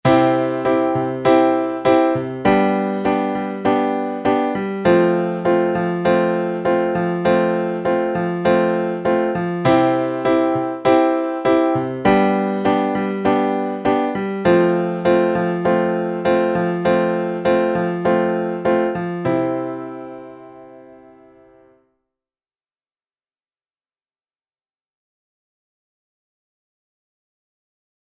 I – V – IV